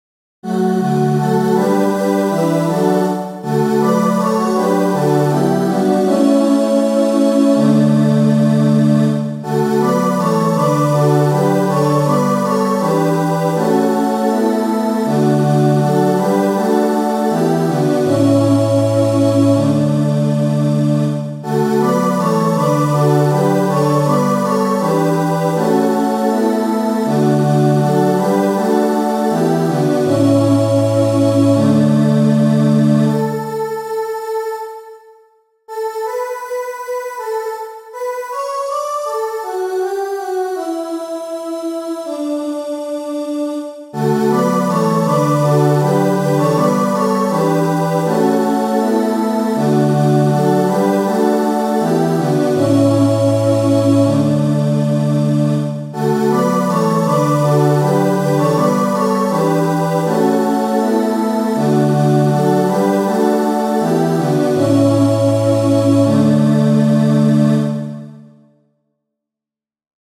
• Catégorie : Chants de Communion